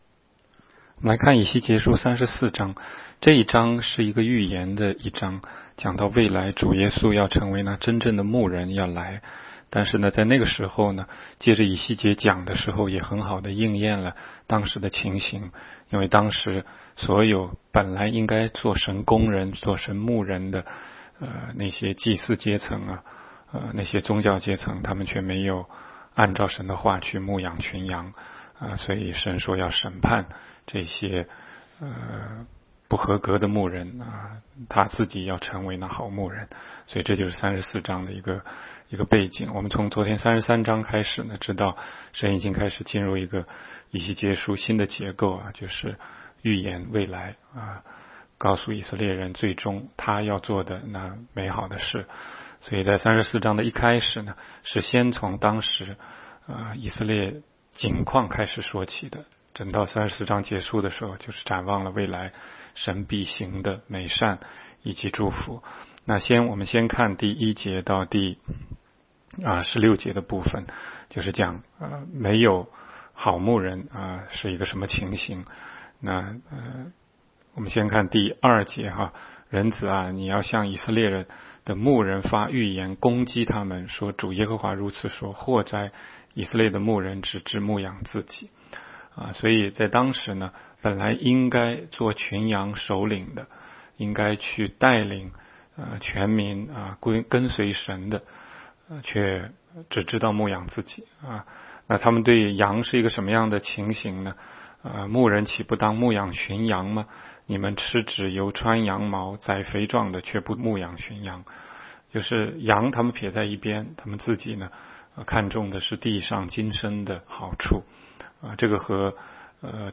16街讲道录音 - 每日读经 -《以西结书》34章